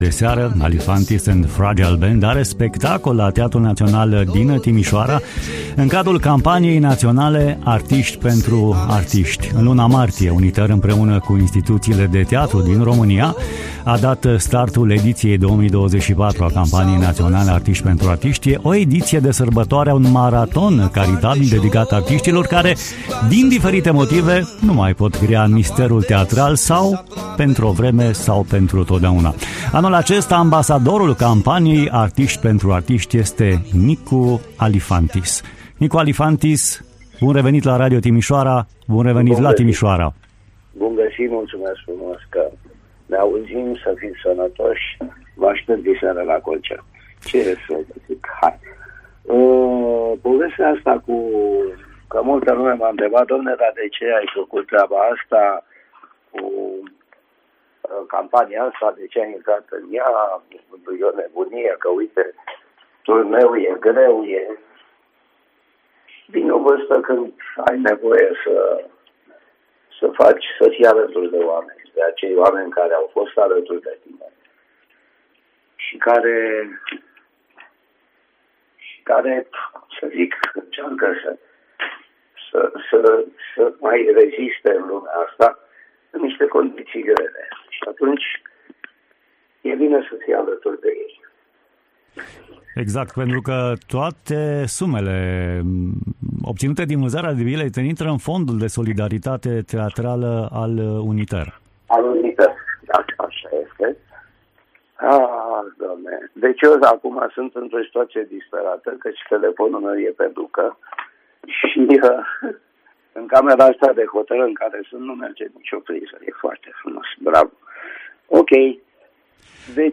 Update 25 iunie: Nicu Alifantis a fost în direct la ”Bună dimineața, Vest!”